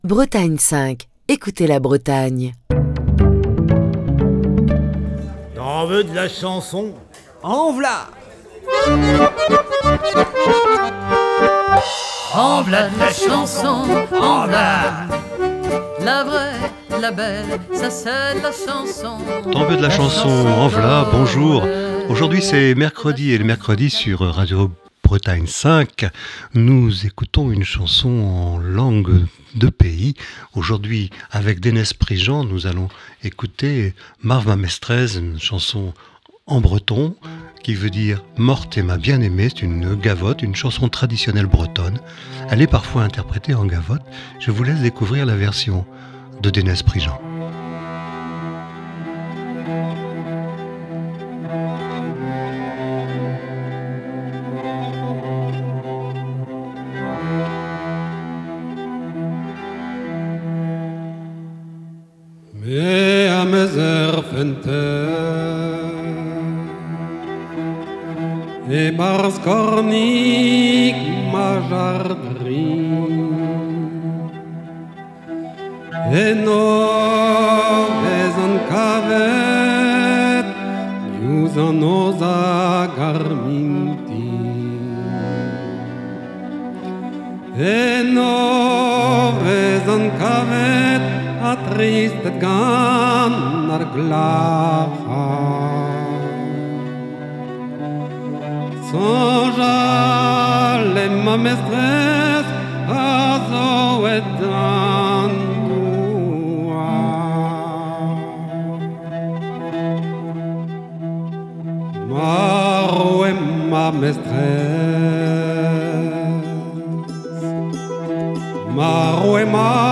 une gwerz